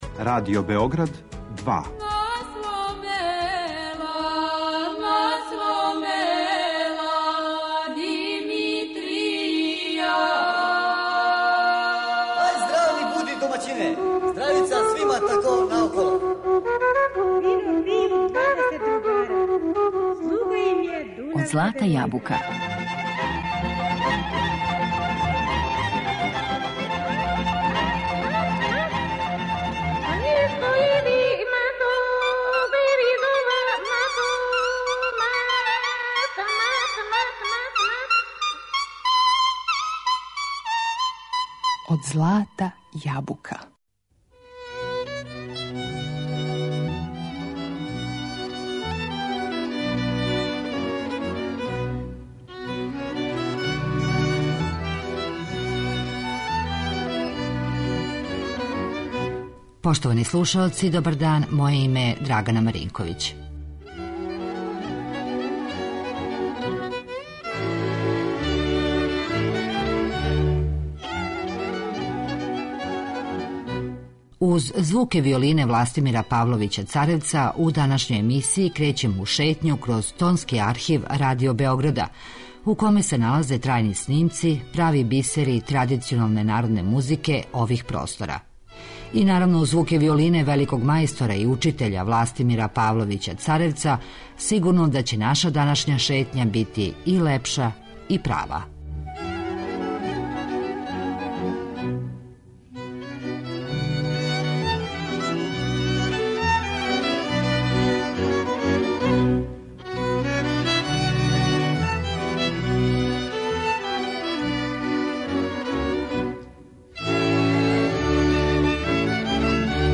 У данашњој емисији говоримо о првим певачима који су снимали за Тонски архив Радио Београда уз пратњу оркестра Властимира Павловића Царевца.